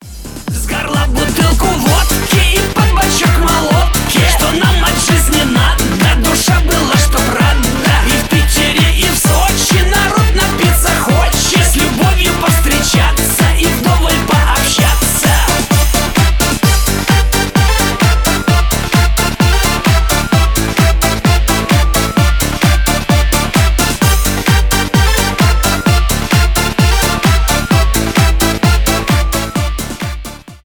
веселые , шансон